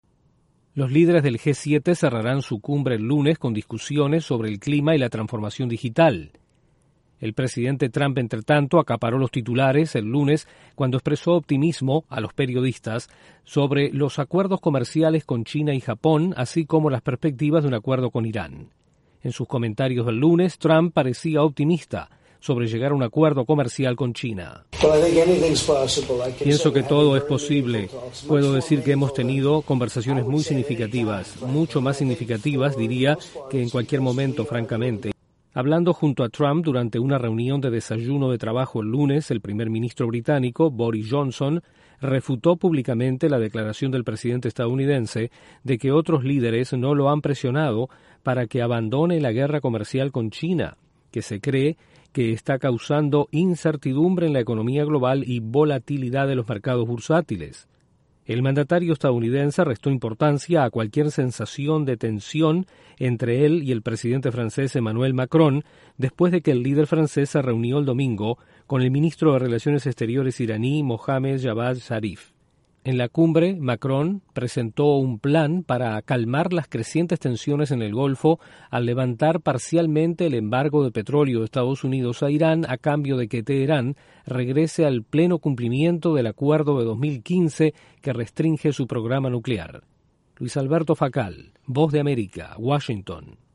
El presidente de EE.UU., Donald Trump, expresa optimismo sobre acuerdo comercial con China al finalizar la cumbre del G-7 en Francia. Informa